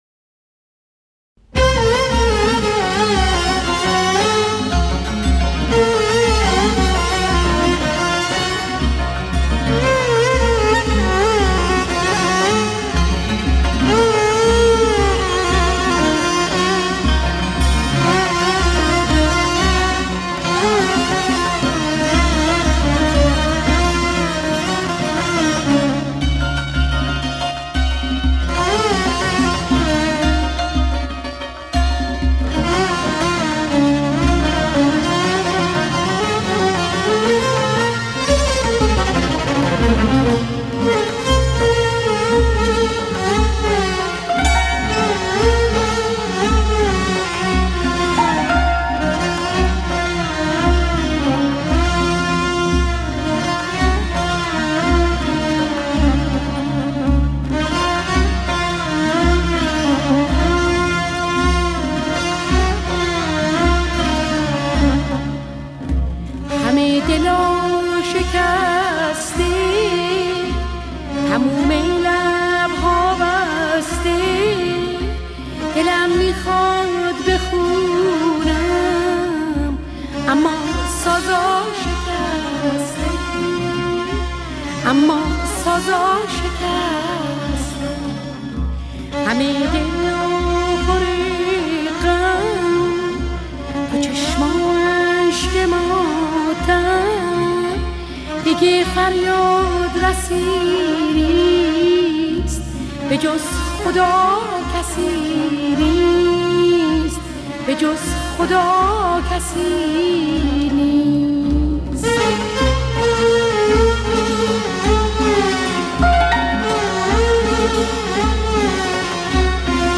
اهنگ ایرانی
اهنگ غمگین